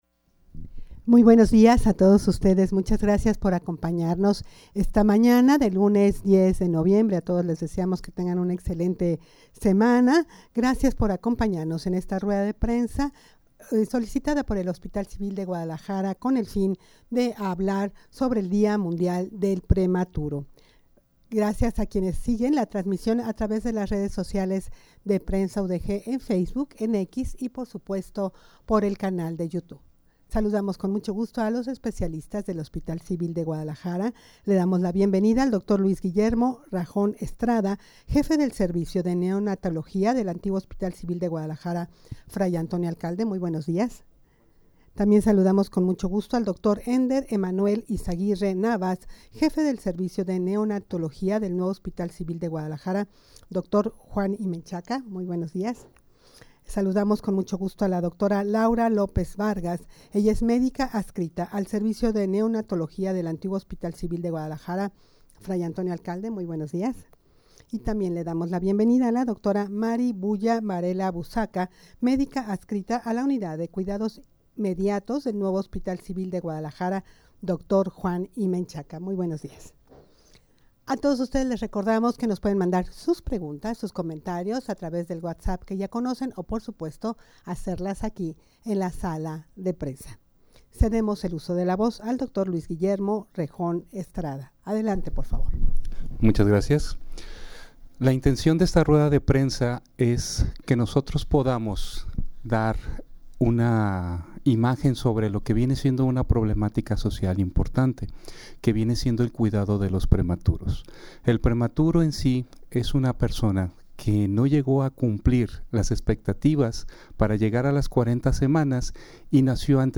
Audio de la Rueda de Prensa
rueda-de-prensa-con-motivo-del-dia-mundial-del-prematuro.mp3